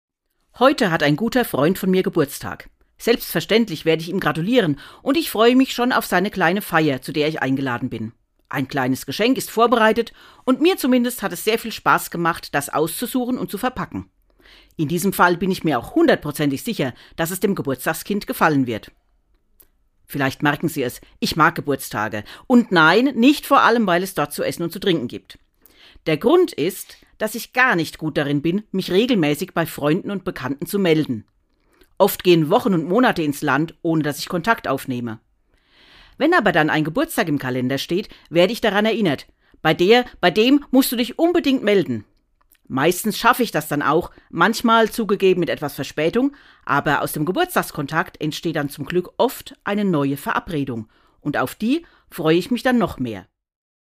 Autorin und Sprecherin